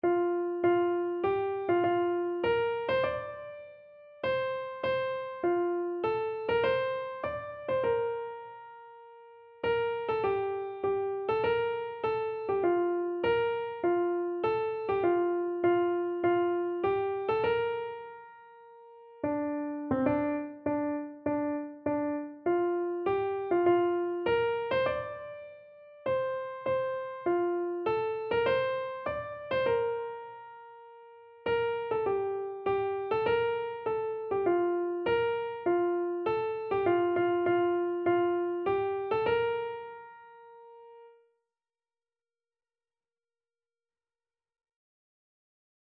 Christian
Free Sheet music for Keyboard (Melody and Chords)
4/4 (View more 4/4 Music)
Keyboard  (View more Easy Keyboard Music)
Classical (View more Classical Keyboard Music)